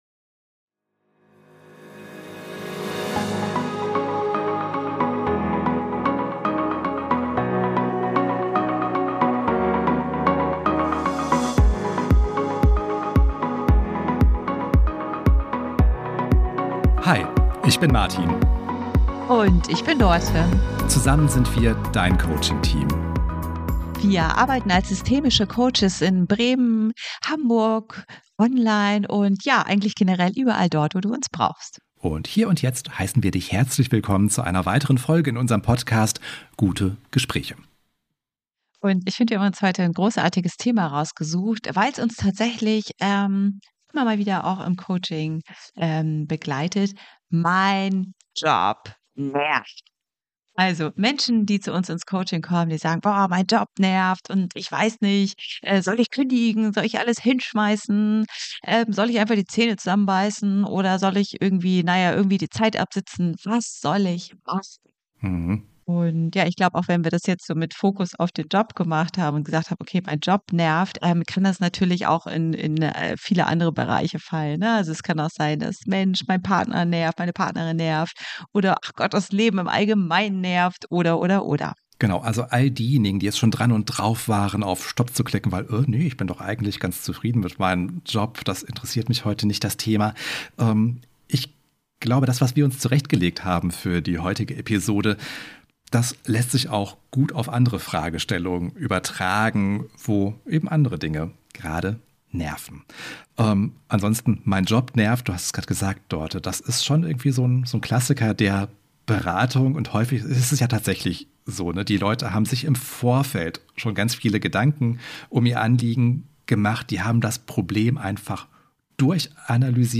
Ein gutes Gespräch über das große Warum und die Frage: Wie könnte es auch anders aussehen?